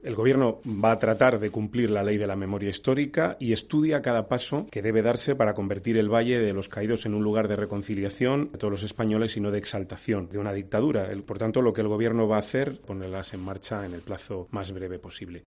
Óscar Puente, portavoz de la ejecutiva del PSOE sobre el Valle de los Caídos
"Es el momento de tomar esas decisiones y las vamos a tomar, sin urgencias, sin precipitaciones y generando las menores estridencias posibles", ha sostenido Puente en rueda de prensa en Ferraz, donde esta mañana se ha reunido la comisión permanente de la ejecutiva del PSOE por primera vez desde que Pedro Sánchez es presidente.